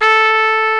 TRUMPET 1 A3.wav